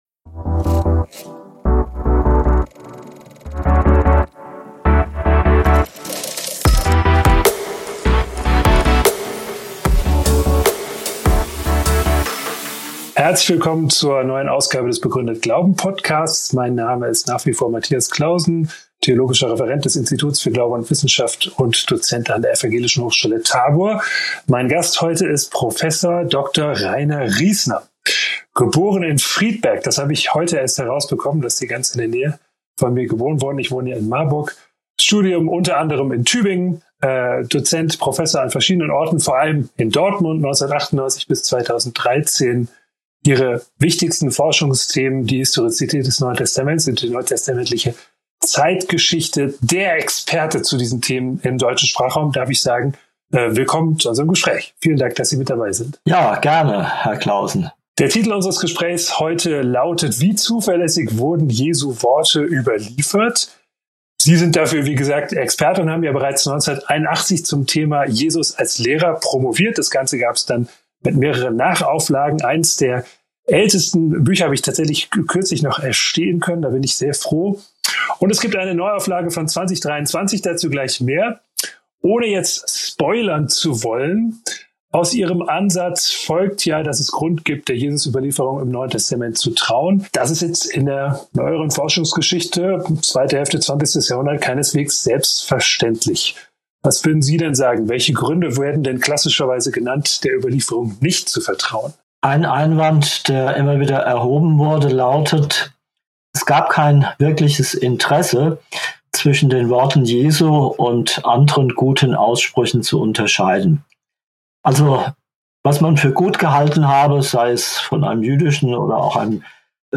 Hirnforschung und Metamoderne_ein Gespräch mit dem Neurowissenschaftler Dr. Gerald Hüther – Begründet Glauben – Lyssna här